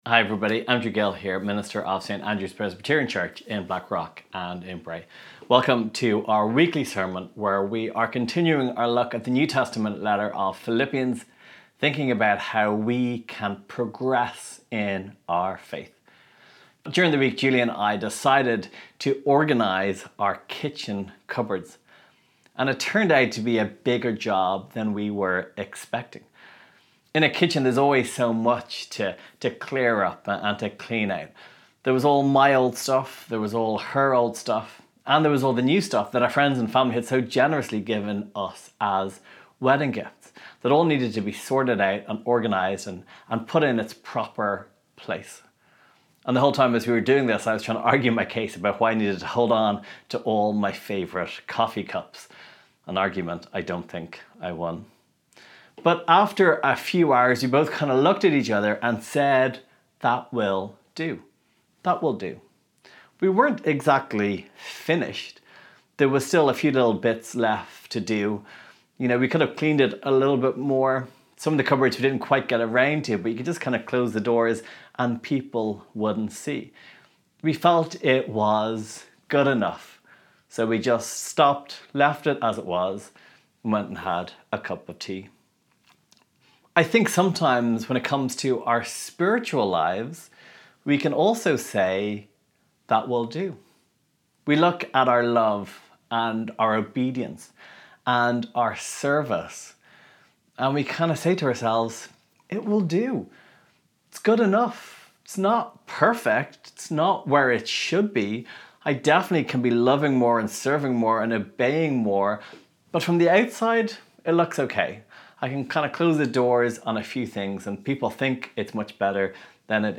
Welcome to our ‘Church at Home’ services for Sunday 4th October.